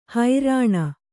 ♪ harāṇa